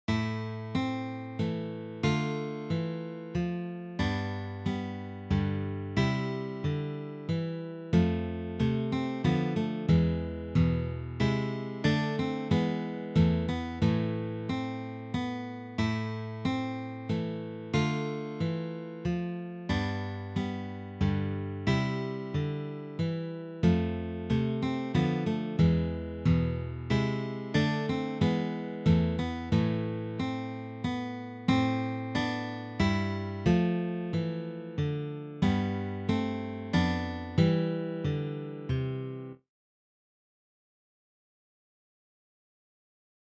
for three guitars